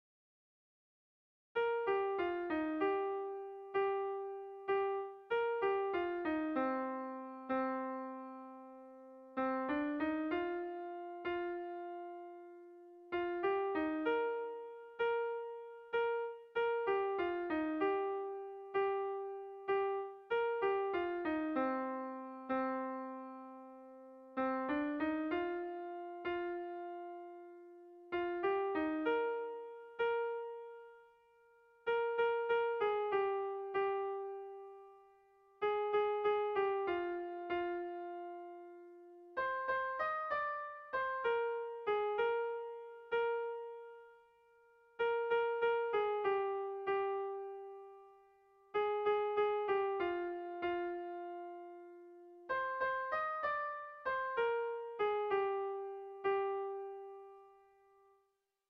Kontakizunezkoa
Zortziko handia (hg) / Lau puntuko handia (ip)
AABB